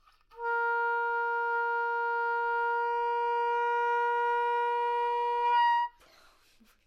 双簧管单音（弹得不好） " 双簧管 A4 坏的动力学坏的伸缩坏的音高
描述：在巴塞罗那Universitat Pompeu Fabra音乐技术集团的goodsounds.org项目的背景下录制。单音乐器声音的Goodsound数据集。
Tag: 好声音 单注 多样本 Asharp4 纽曼-U87 双簧管